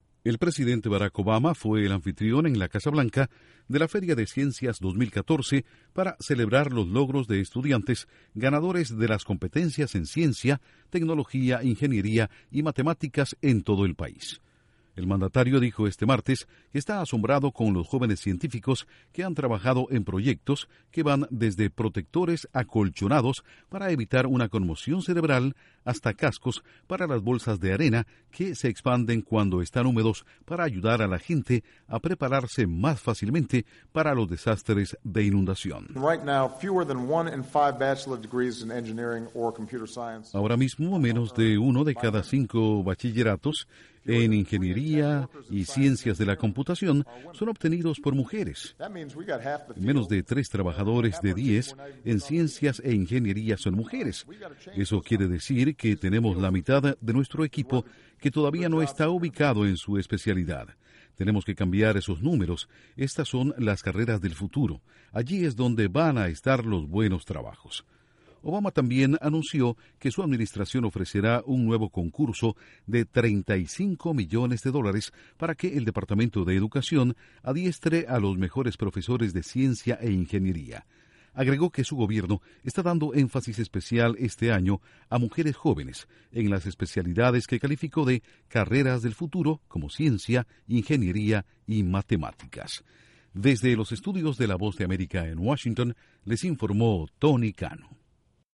Intro: El Presidente Barack Obama rindió un homenaje a jóvenes estudiantes de todo el país por el aporte que hacen a Estados Unidos con innovadores proyectos. Informa desde la Voz de América en Washington